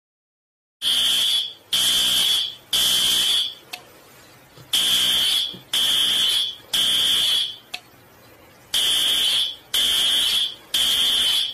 Theatre Project Fire Alarm